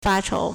发愁 (發愁) fāchóu
fa1chou2.mp3